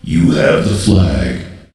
voc_you_flag.ogg